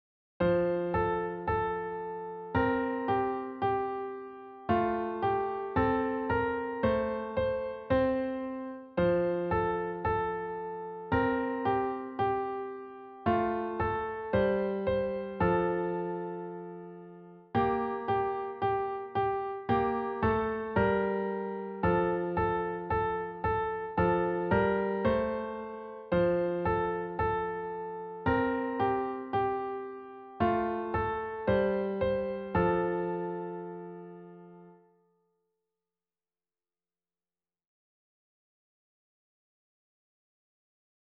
Early Elem